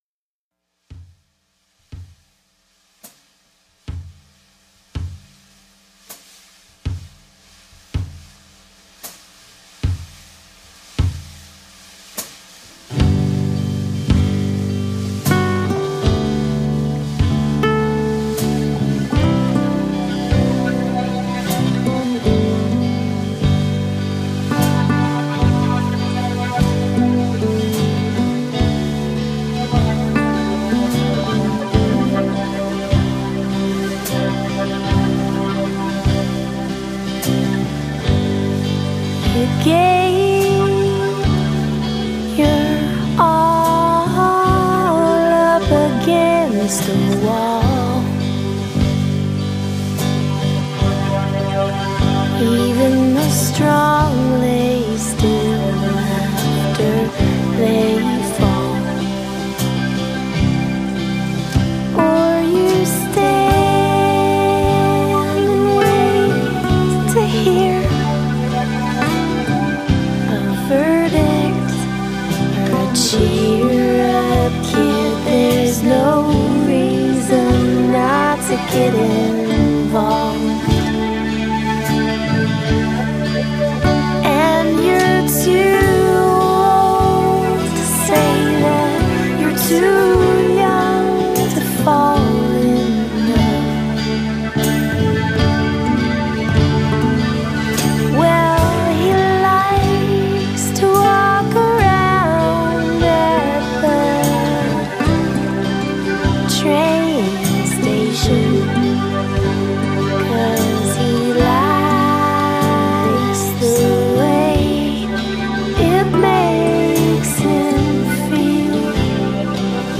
Recorded @ Ultrasound studios in Chattanooga Tn.
Rock & Roll
Unplugged